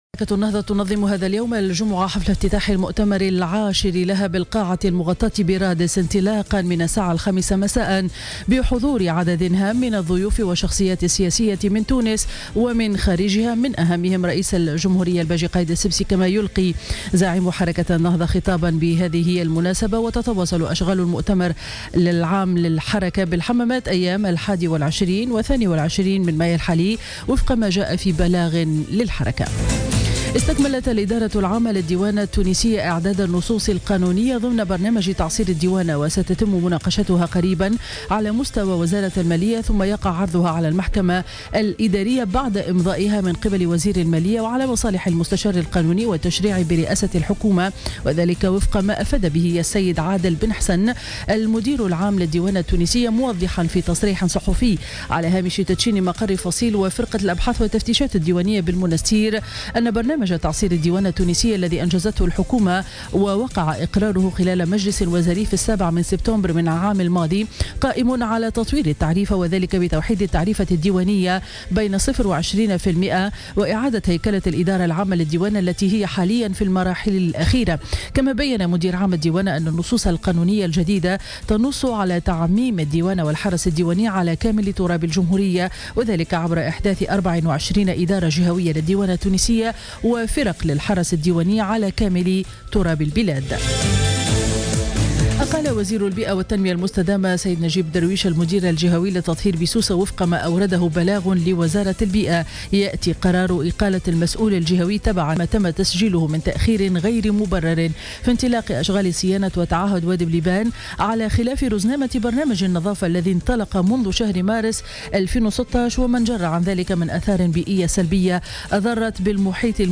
نشرة أخبار السابعة صباحا ليوم الجمعة 20 ماي 2016